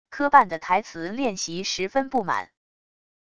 磕绊的台词练习十分不满wav音频